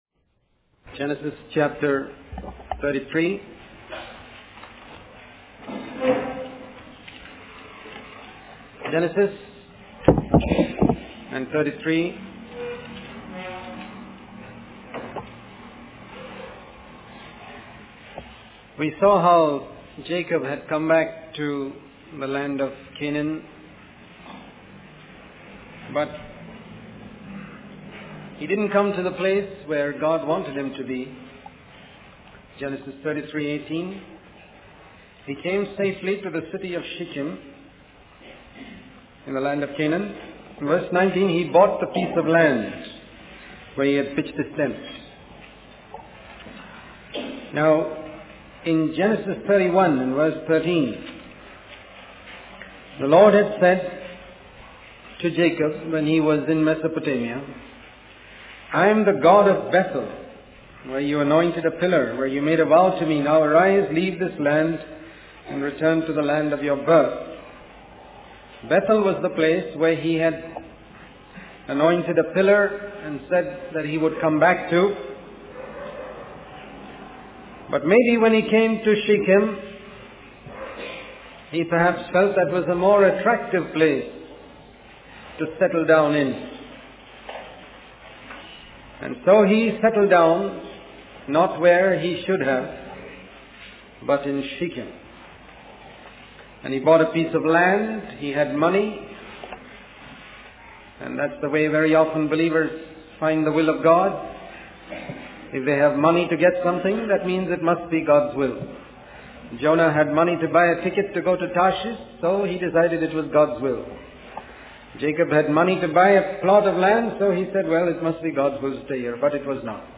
Ultimately, the sermon calls for a wholehearted commitment to God's purpose, urging believers to remove idols and distractions from their l